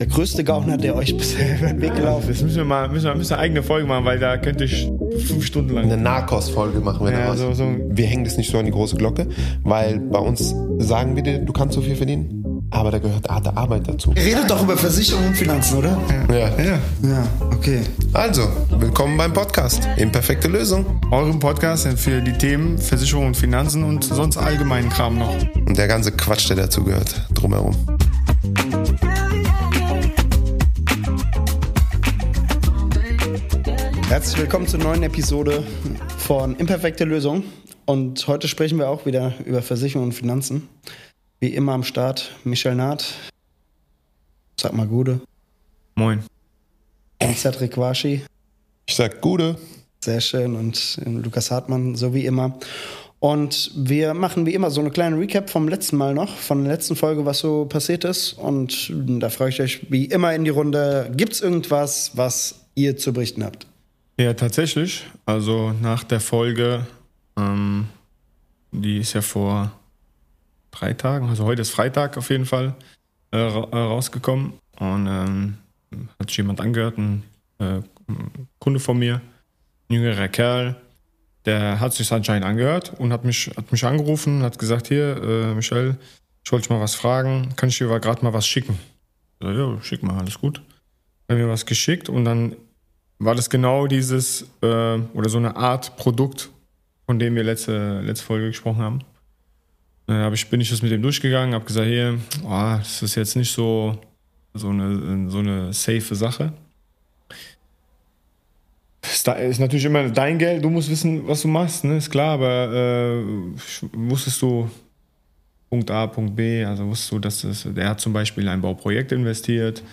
Reinhören lohnt sich – jetzt mit neuem Intro, aber gewohnten Stimmen!